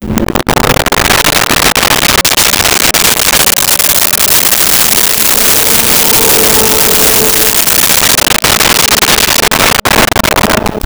Race Car Fast By
Race Car Fast By.wav